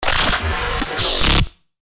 FX-1579-SCROLLER
FX-1579-SCROLLER.mp3